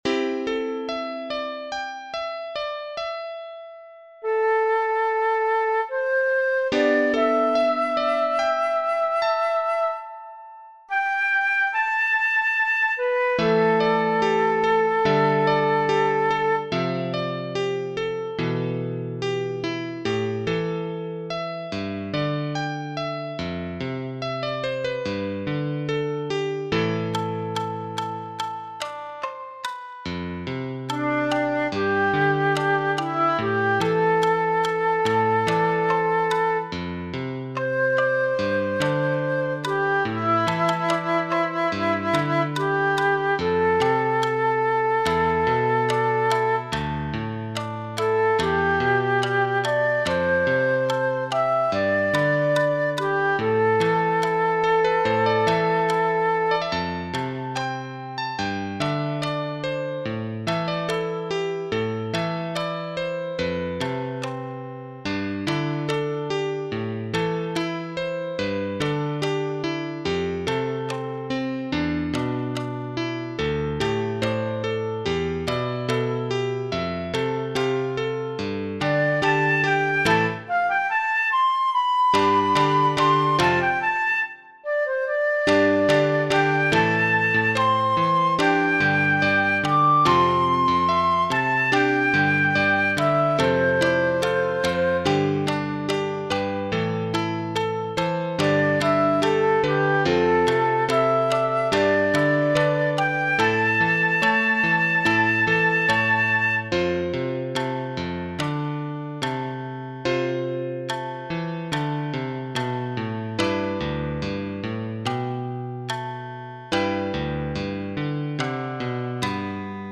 No.10 冬渡り（ふゆわたり） 【編成】箏・十七絃・三絃・尺八（一尺八寸管）  ※ソロが二声部に分かれるため、尺八奏者は2人以上を推奨  人生の岐路に立つ後輩たちへ贈った曲です 。
前半はゆったりと静かな冬空を想起させ、そこからテンポを切り替えてノリ良く展開していきます。 そして後半は厳しい寒さの中を進み、その先にある春へ辿り着くシーンを描いています。
個々のパートで技巧を見せつけるような手は入れていませんが、一斉ミュートや掛け合いが多く、上々のチームワークが求められる曲に仕上がったかと思います。